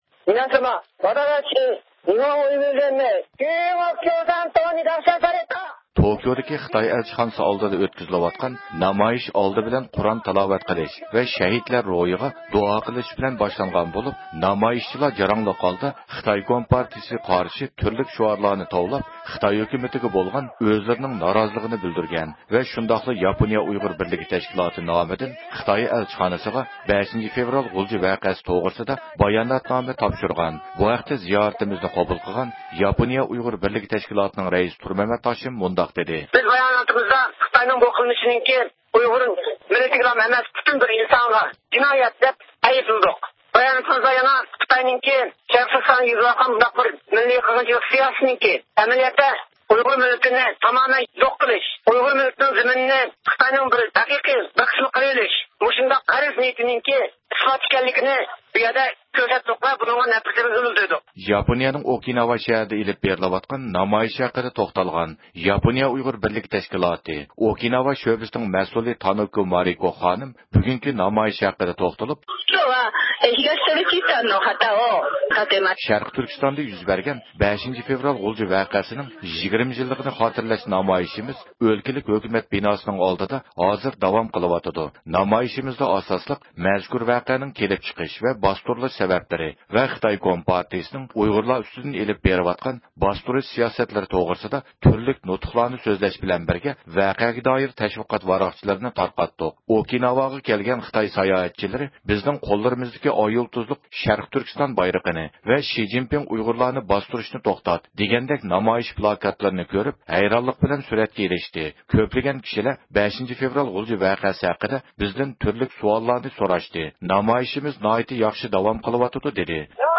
ياپونىيەنىڭ توكيو، ئوساكا، فۇكۇئوكا، ناگاساكى ۋە ئوكىناۋا شەھەرلىرىدە 5-فېۋرال غۇلجا ۋەقەسىنى خاتىرىلەش مەقسىتىدە ئېلىپ بېرىلىۋاتقان نامايىشلار ھەققىدە تولۇق مەلۇماتقا ئېرىشىش ئۈچۈن،  ئەركىن ئاسىيا رادىئوسى مۇخبىرى ھەر قايسى شەھەرلەردىكى نامايىش مەيدانلىرىغا تېلېفون قىلىپ بۇ ھەقتە بىر قىسىم ئۇچۇرلارغا ئىگە بولغان.